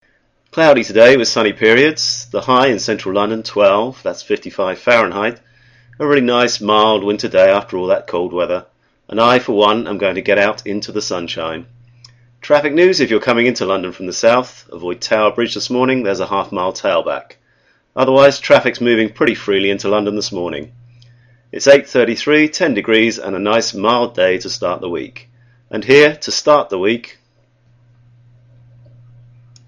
Descarga de Sonidos mp3 Gratis: radio anuncio.